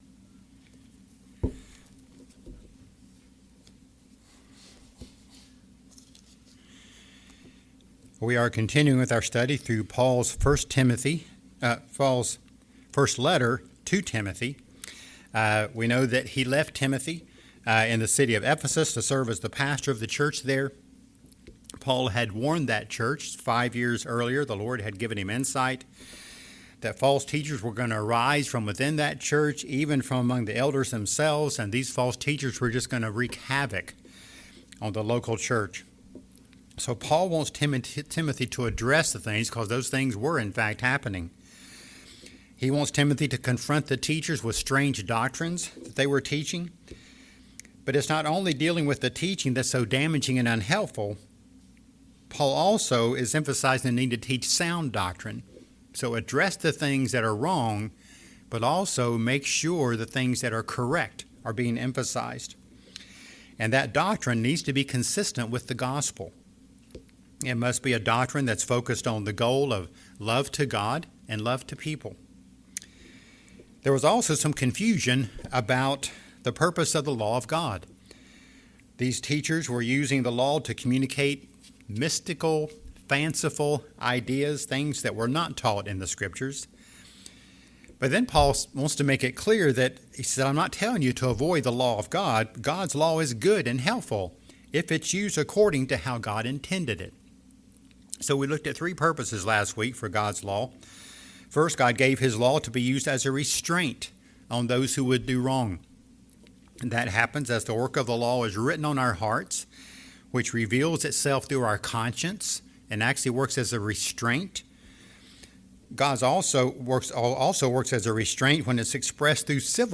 The Glorious Gospel | SermonAudio Broadcaster is Live View the Live Stream Share this sermon Disabled by adblocker Copy URL Copied!